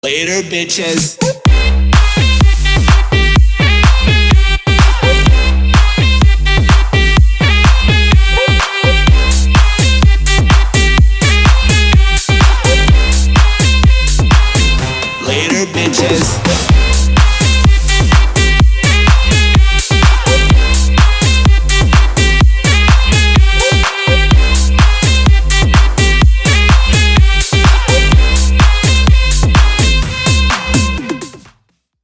• Качество: 320, Stereo
громкие
зажигательные
EDM
future house
Big Room
Зажигательный ремикс зажигательной хитовой песни